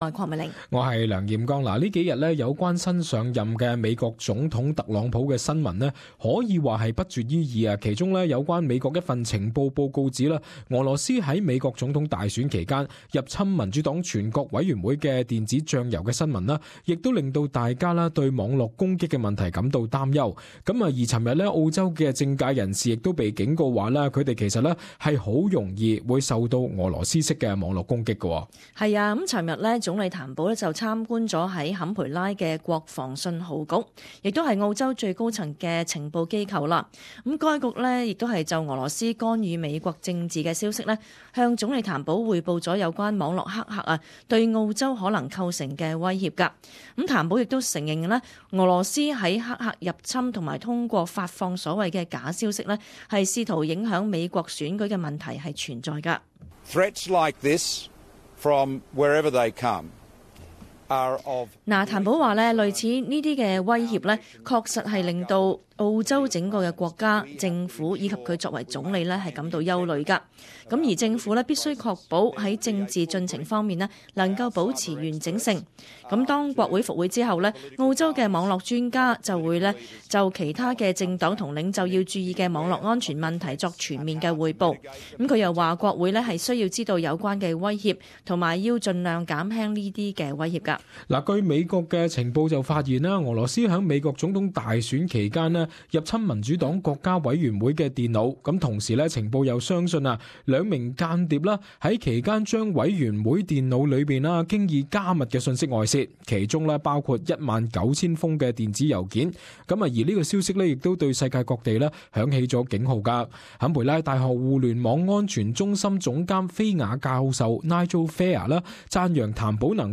【时事报导】澳洲政客极可能受到俄罗斯式网络攻击？